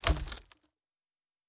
pgs/Assets/Audio/Fantasy Interface Sounds/Wood 02.wav at master
Wood 02.wav